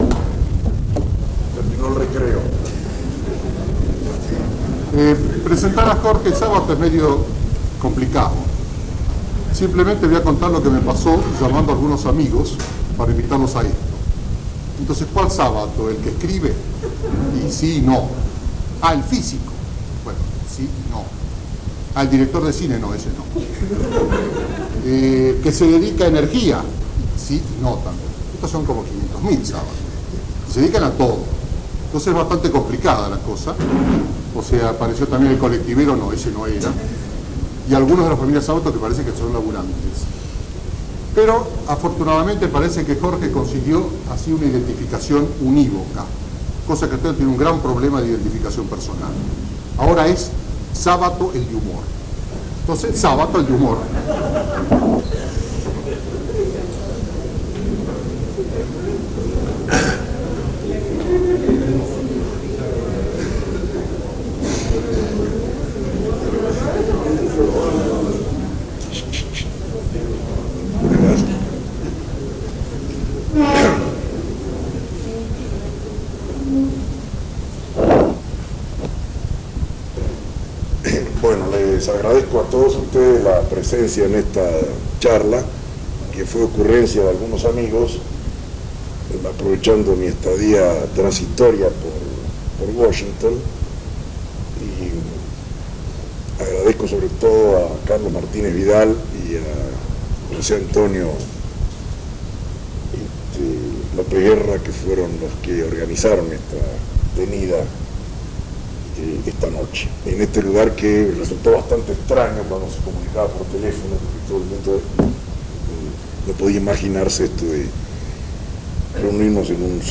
Conferencia realizada en Washington DC, USA el 20/01/1983.